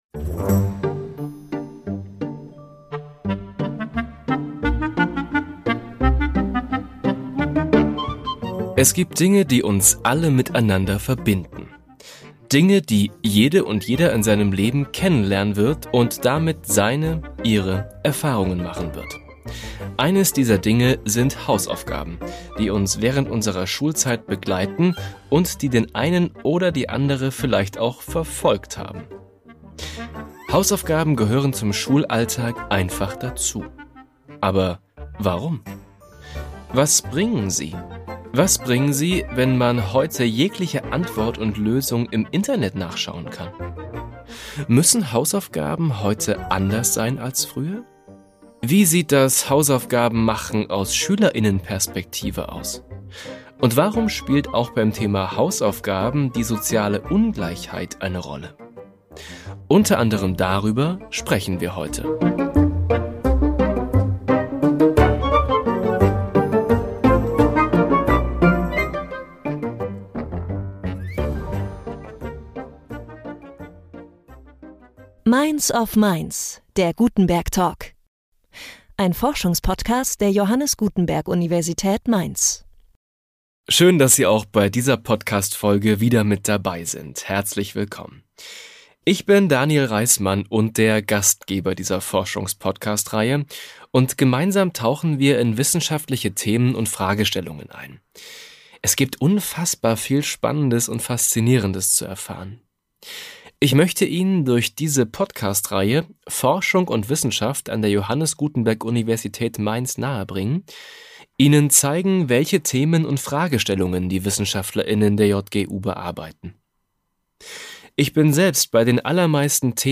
im Gespräch